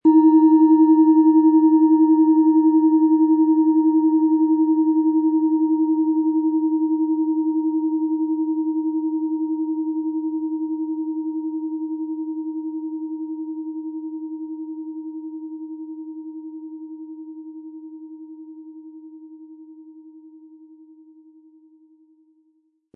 Die Klangschale wurde nach alter Tradition von Hand getrieben.
Unter dem Artikel-Bild finden Sie den Original-Klang dieser Schale im Audio-Player - Jetzt reinhören.
Den passenden Schlegel erhalten Sie kostenfrei mitgeliefert, der Schlägel lässt die Schale voll und wohltuend erklingen.
SchalenformBihar
MaterialBronze